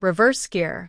reverse_gear.wav